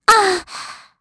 Gremory-Vox_Damage_kr_02.wav